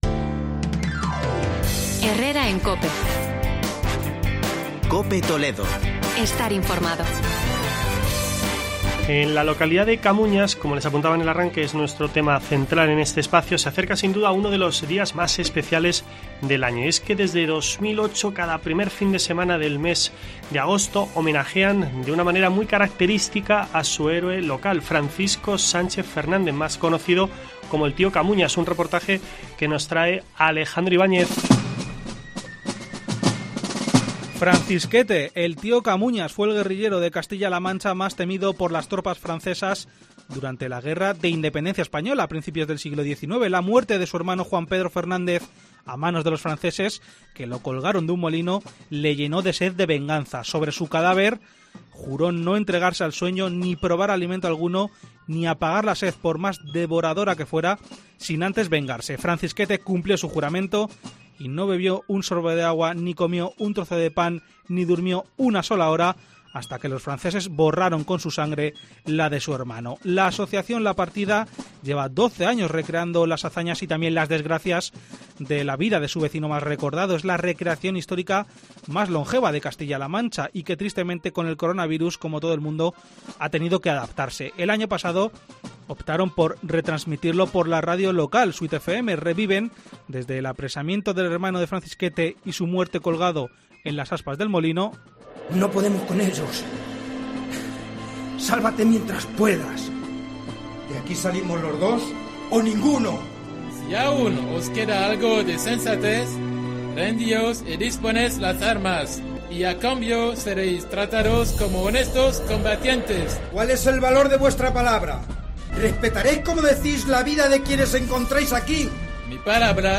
Reportaje recreación 'El Tío Camuñas'